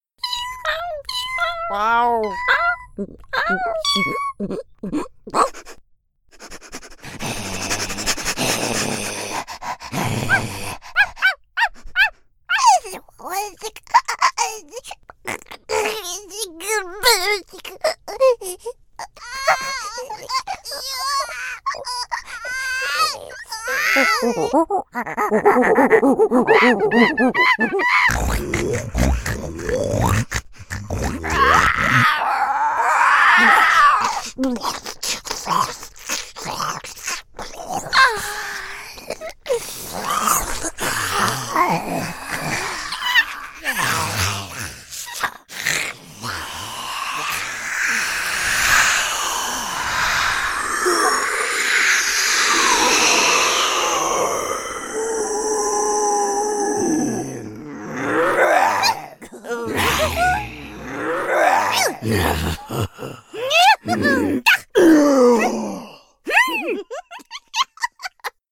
Voice Actor
Creature